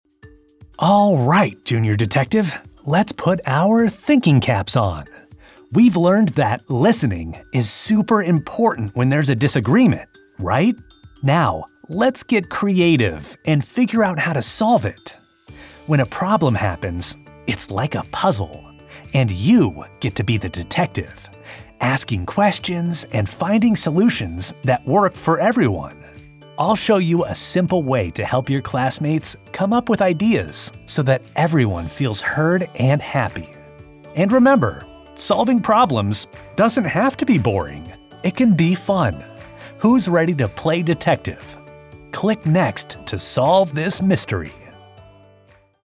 NARRATION
A curious and upbeat guide who turns problem-solving into an exciting mystery, encouraging students to think critically and have fun while learning.